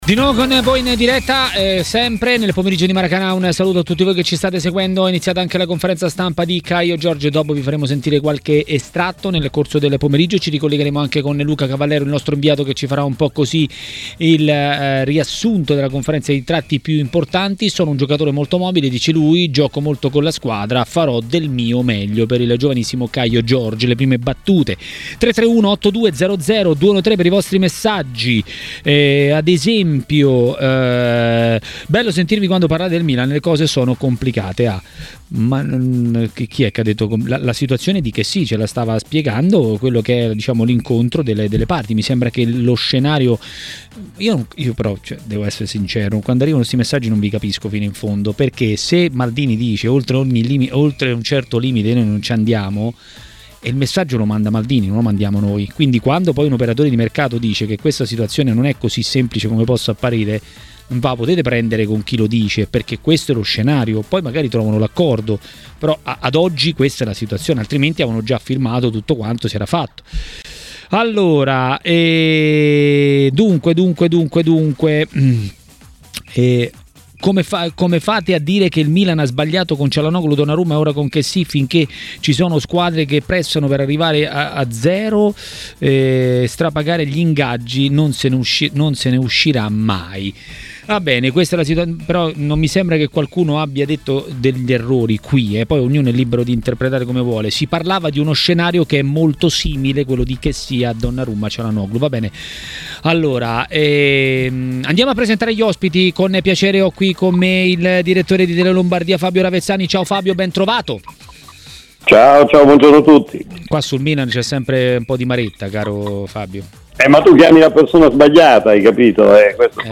registrazione di TMW Radio